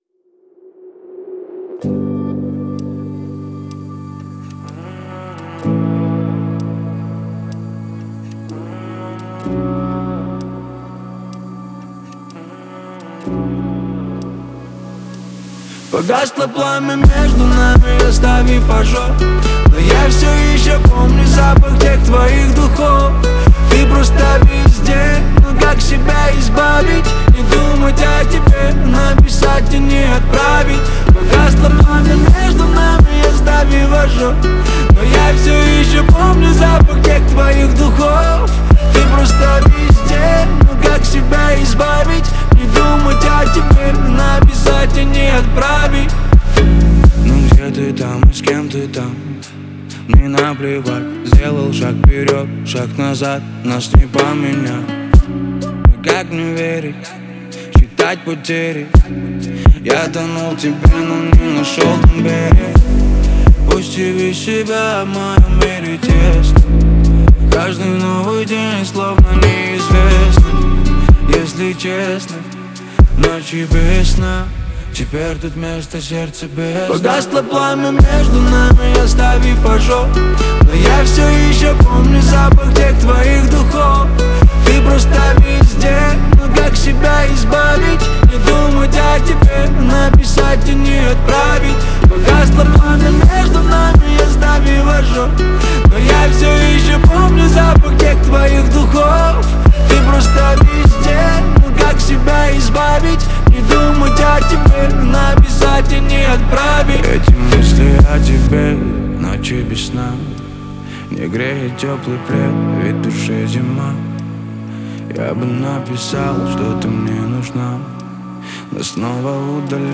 это трек в жанре поп с элементами лирики